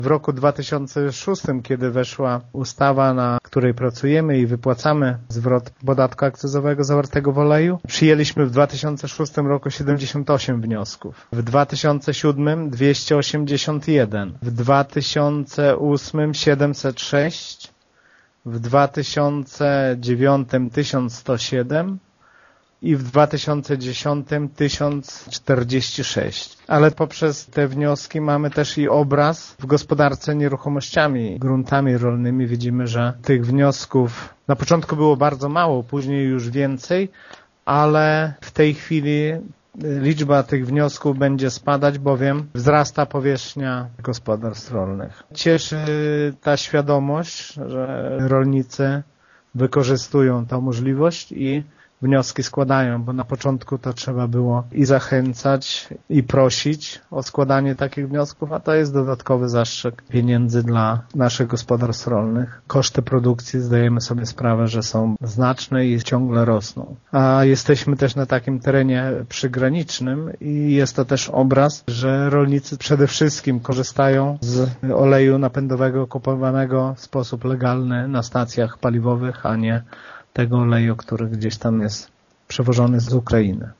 Jeszcze kilka lat temu, w gminie Mircze, z takiej możliwości skorzystało zaledwie kilkudziesięciu rolników, a obecnie liczba wniosków przekracza... 1000 – mówi wójt Lech Szopiński: